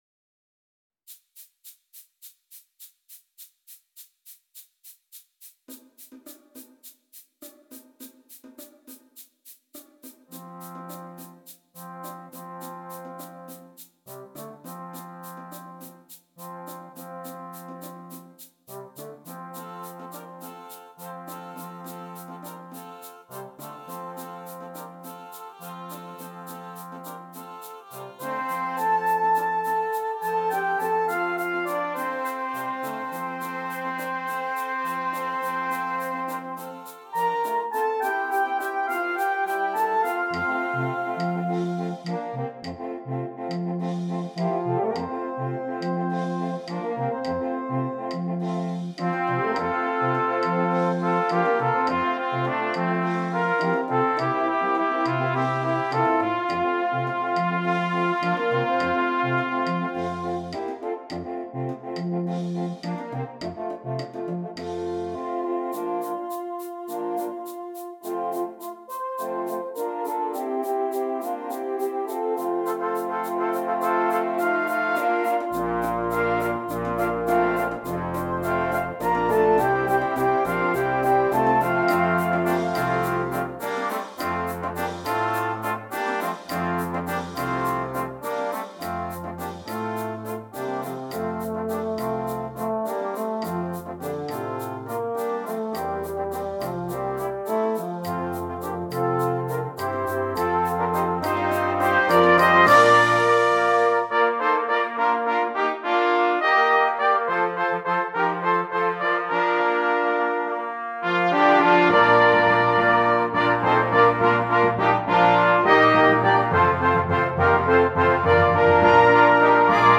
Brass Choir (6.4.3.1.1.perc)